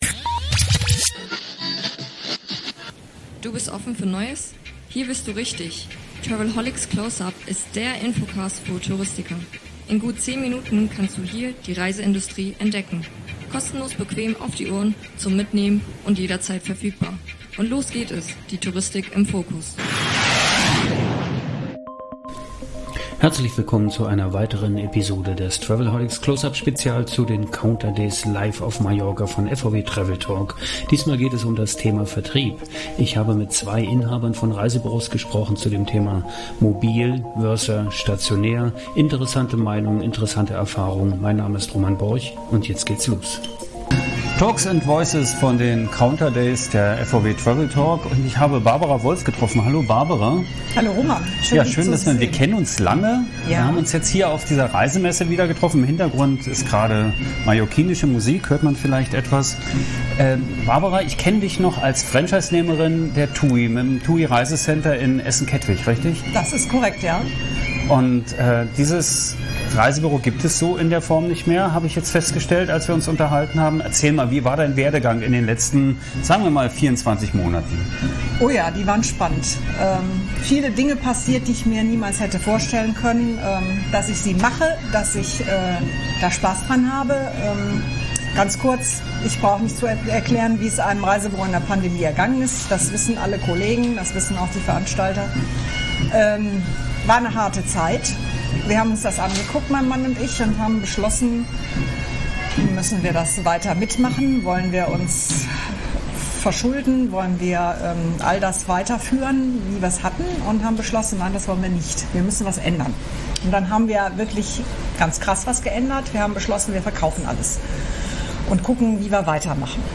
Live Podcasting im Plenum, Stimmen von der Messe, oder ganz spontane Interviews mit Expis, Ausstellern und Partnern.
Der travelholics Podcast war natürlich mit vor Ort und fing "talks ´n voices" ein.